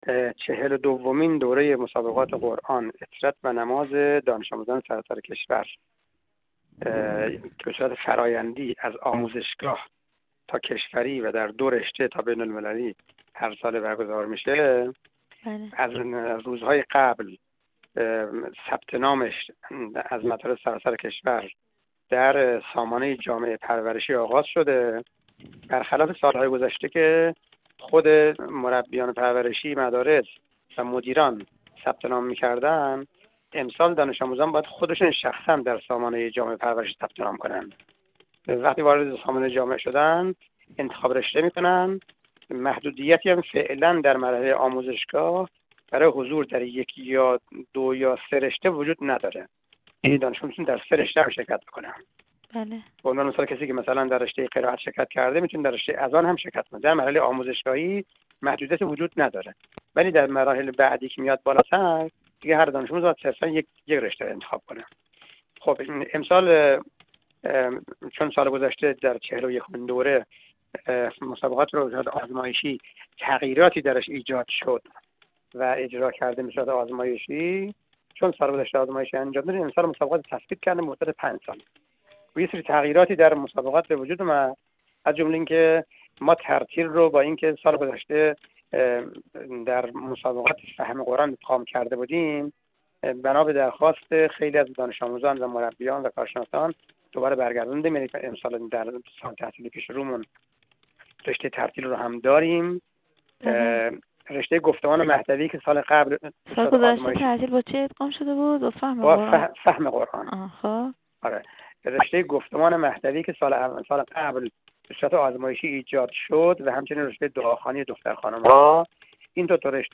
میکائیل باقری، مدیرکل قرآن، عترت و نماز وزارت آموزش و پرورش در گفت‌وگو با ایکنا ضمن بیان این مطلب گفت: ثبت‌نام چهل و دومین دوره مسابقات قرآن، عترت و نماز دانش‌آموزان سراسر کشور آغاز شد و فرصت ثبت‌نام تا هفته قرآن و عترت مدارس، 11 آذرماه ادامه دارد.